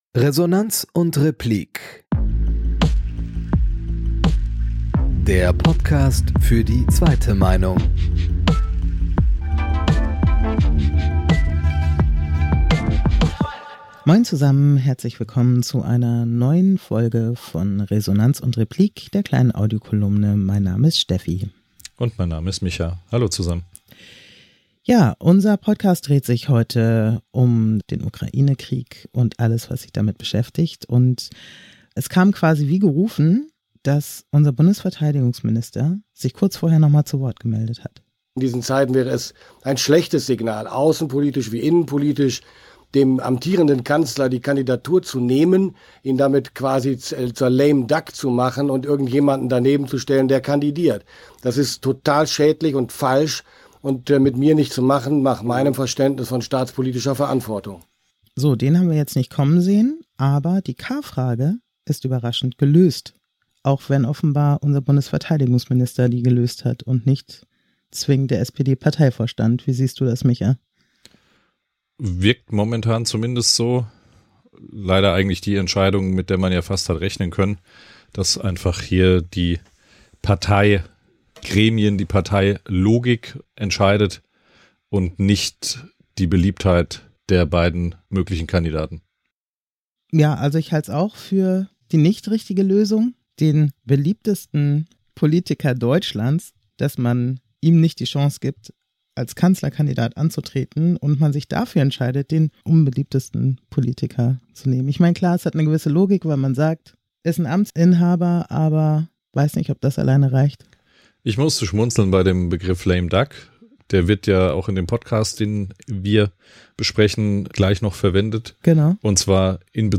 News Talk